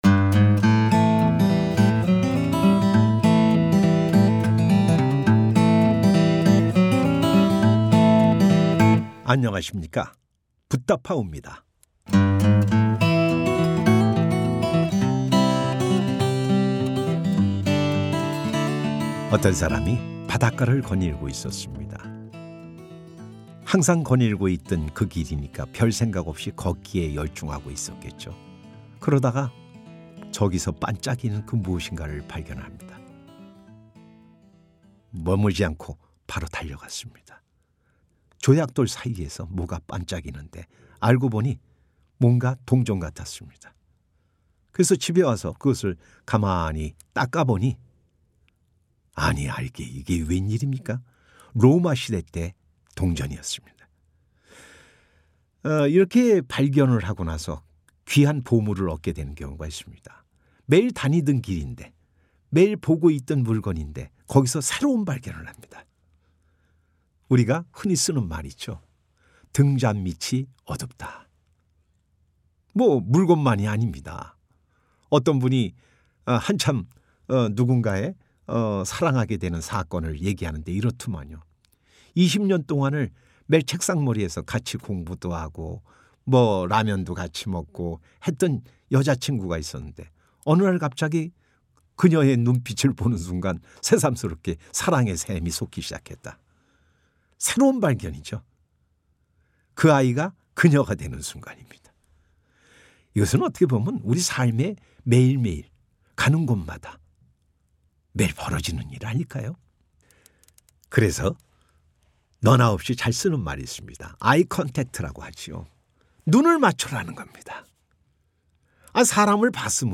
법문